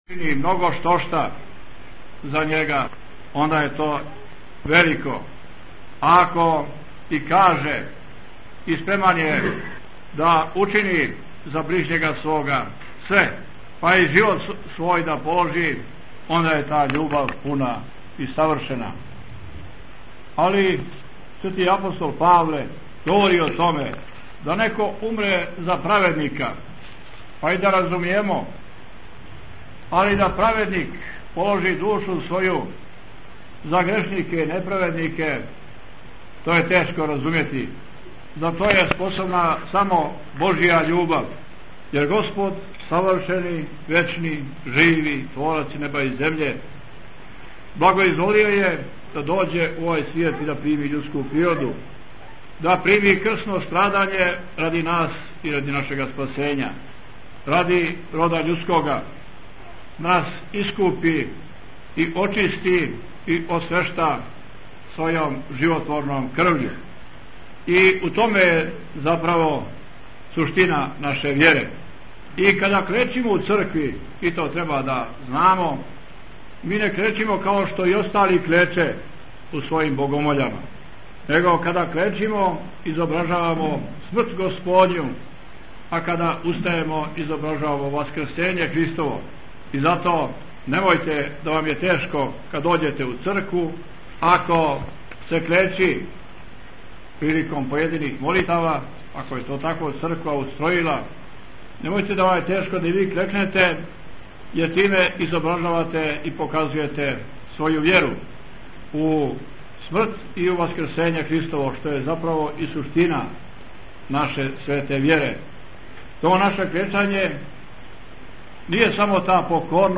Програм Емисије Актуелно Историјат Продукција Маркетинг Награде Линкови Контакт Почетак Бесједе Парастос пострадалима у НАТО агресији 1999.
Преузмите аудио датотеку 768 преузимања 49 слушања Прочитајте више Епископ Г. Јоаникије служио у цркви Преображења Господњег на Жабљаку , 22. март 2010 Tagged: Бесједе 10:11 минута (1.75 МБ) 22. марта 2010. године на празник Светих Четрдесет Мученика Севастијских, Његово Преосвештенство Епископ будимљанско - никшићки Г. Јоаникије служио је Литургију Пређеосвећених Часних Дарова у цркви Преображења Господњег на Жабљаку.